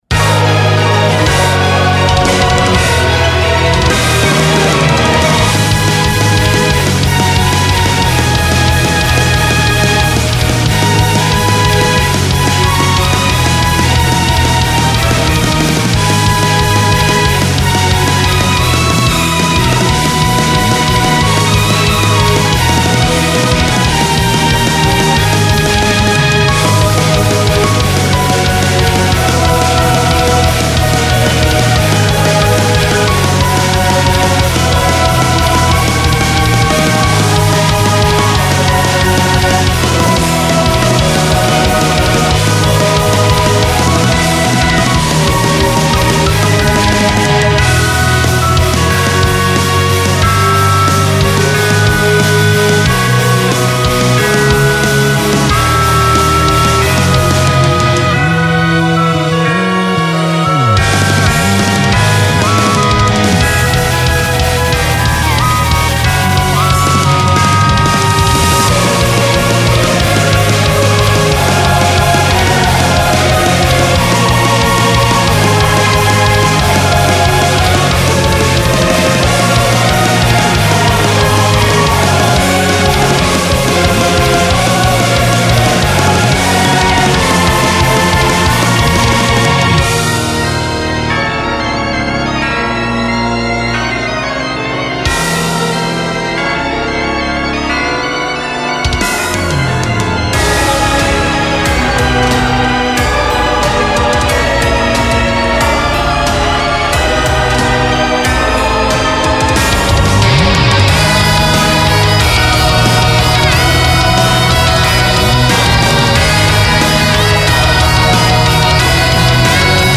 ジャンルゴシック、シンフォニックロック
使用例ボス戦闘曲
BPM１８２
使用楽器ピアノ、オーボエ、クラリネット、ヴァイオリン、ギター
解説シンフォニックロックの戦闘曲フリーBGMです。
ゴシックカテゴリーではありますが、あくまでロックをベースにゴシックと上乗せしたイメージで制作いたしました。
スピード感、強敵感、哀愁ともにありますので、ラスボス戦や負けイベントからの再戦など、幅広くお使い頂けます。